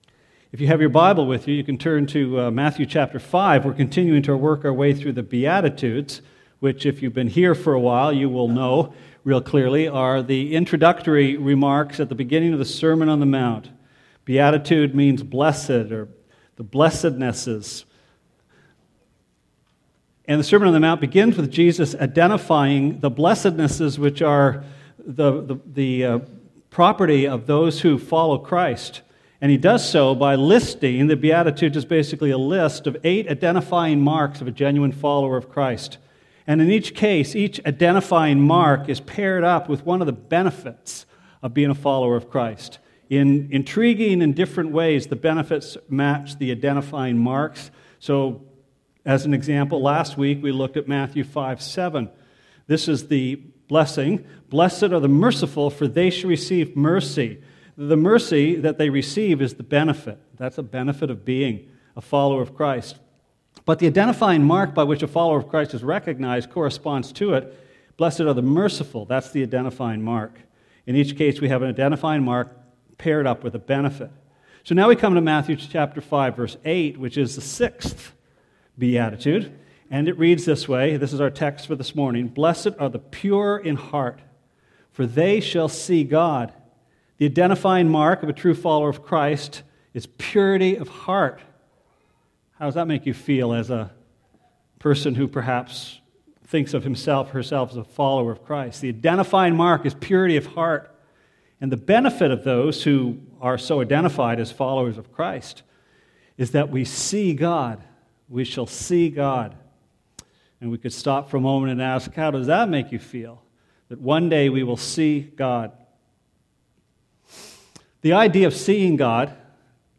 Sermon Archives - West London Alliance Church
In starting his “Sermon on the Mount,” Jesus declares that it is “the pure in heart” who are blessed and that it is they who shall see God. Our Sunday morning study of the Beatitudes continues.